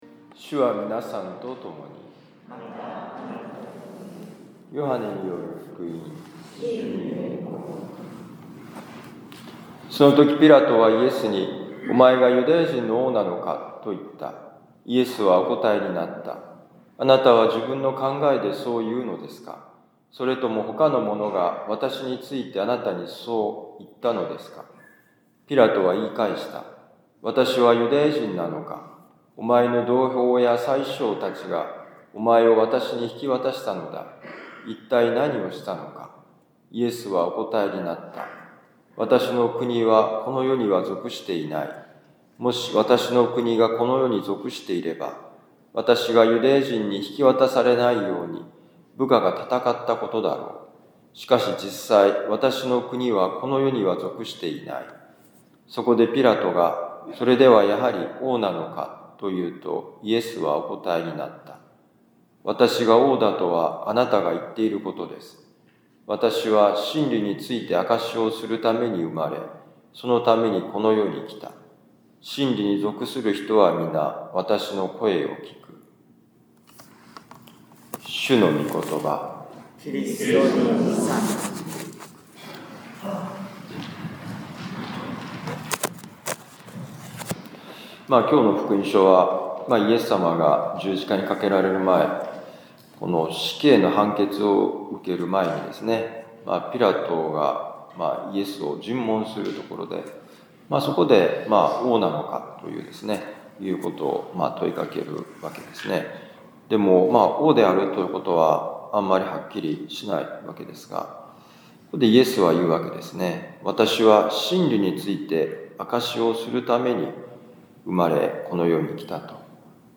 【ミサ説教】
ヨハネ福音書18章33b-37節「ポスト真実の時代にイエスの真実を見つめて」2024年11月24日王であるキリストの主日ミサ六甲カトリック教会